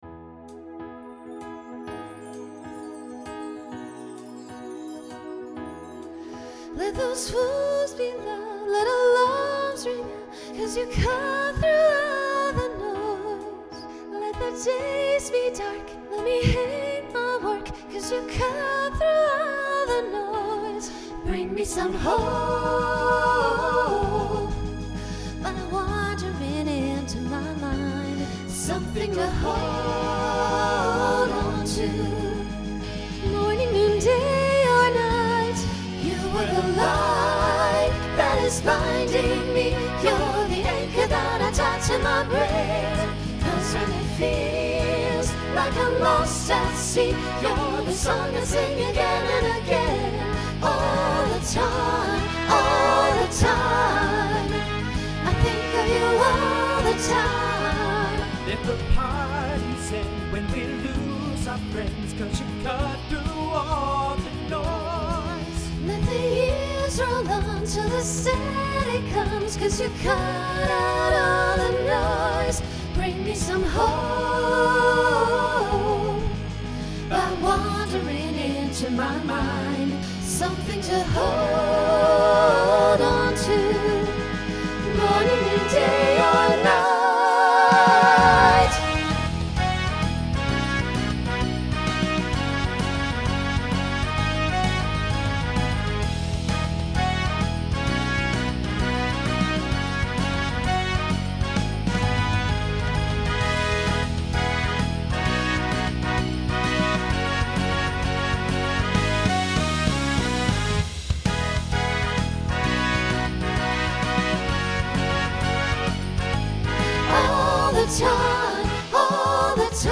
Rock Instrumental combo
Mid-tempo , Opener Voicing SATB